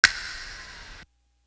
Great Outdoors Snap.wav